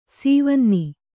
♪ sīvanni